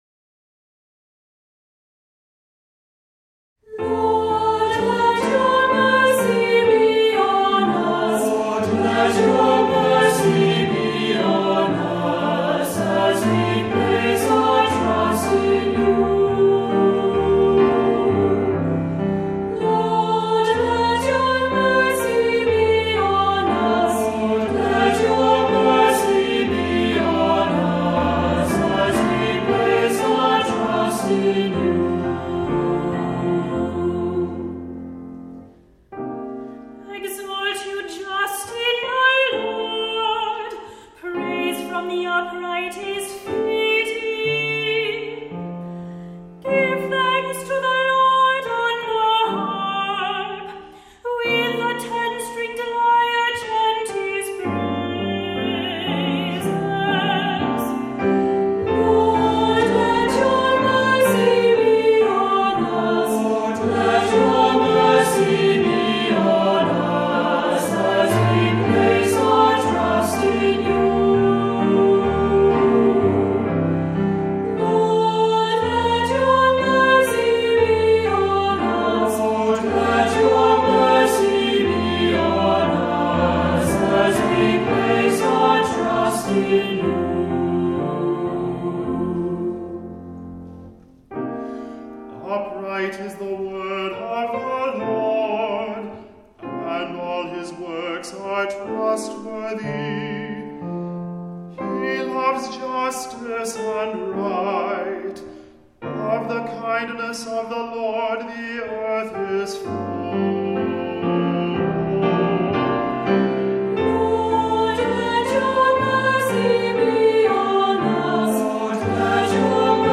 Voicing: Two-part Mixed Choir; Cantor Assembly